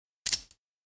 手枪格洛克没子弹.mp3